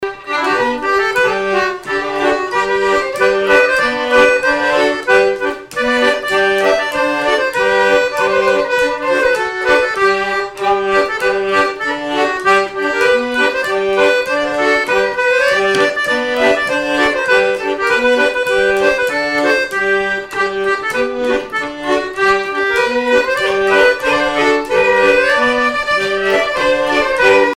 Répertoire de bal au violon et accordéon
Pièce musicale inédite